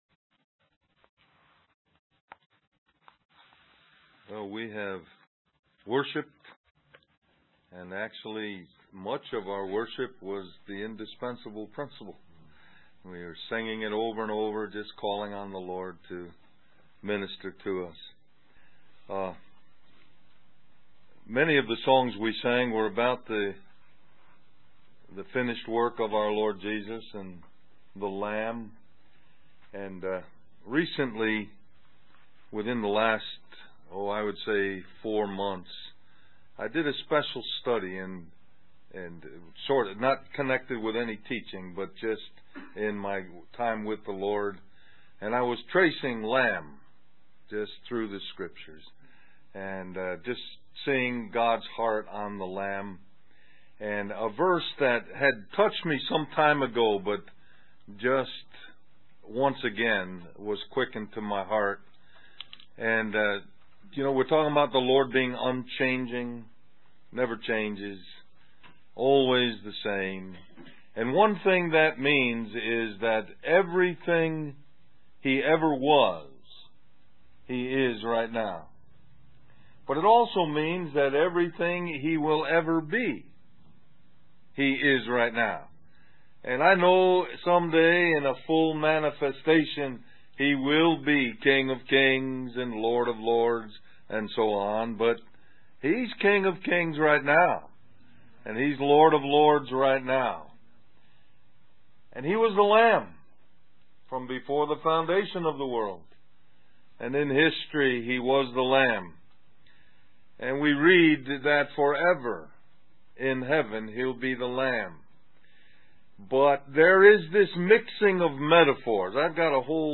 Del Mar Va Mens Retreat 2003 List